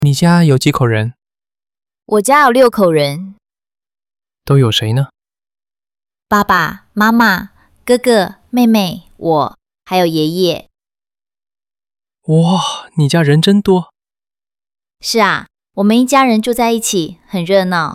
🗣 Bài hội thoại: 家里有几口人？(Nhà bạn có mấy người?)
hội thoại nhà bạn có bao nhiêu người tiếng trung